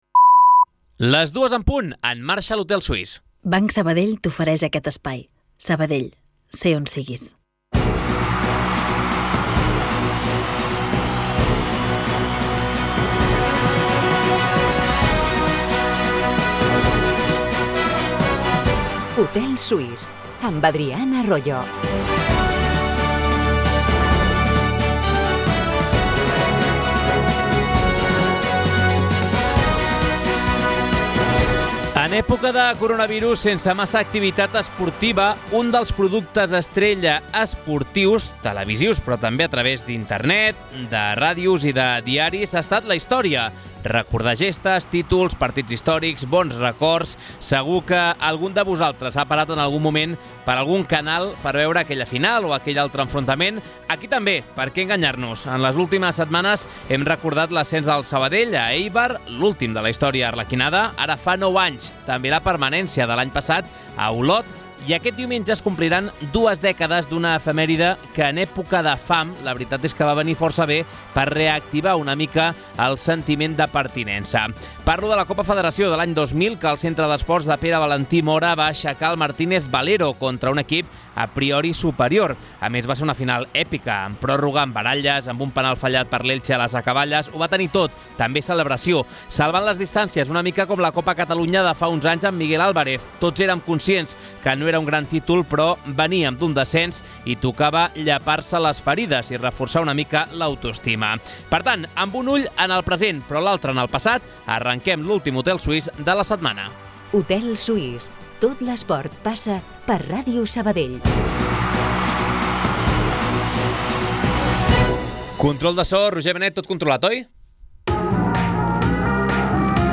Les tertúlies esportives del recordat Hotel Suís de Sabadell prenen forma de programa de ràdio. Com passava llavors, l'hotel es converteix en l'espai reservat per a la reflexió, el debat i la conversa al voltant de l'esport de la ciutat.